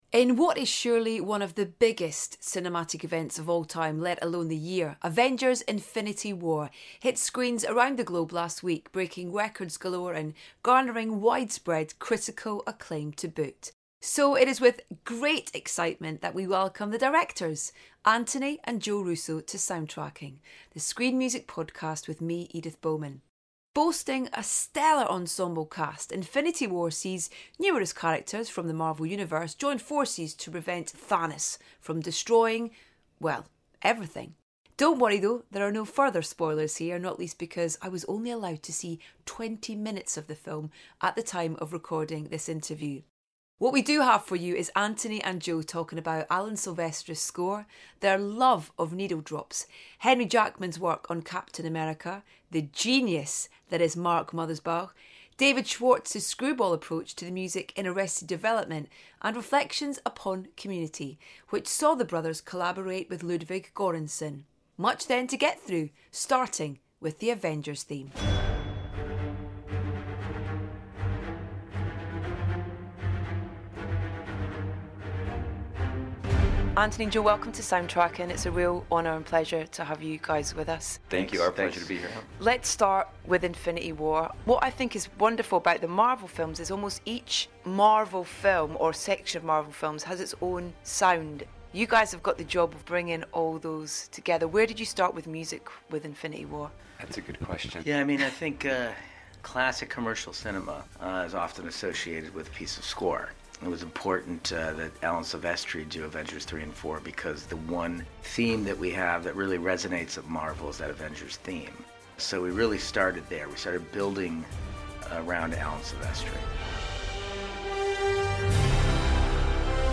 Don't worry, though, there are no further spoilers here, not least because Edith was only allowed to see 20 minutes of the film at the time of recording the interview.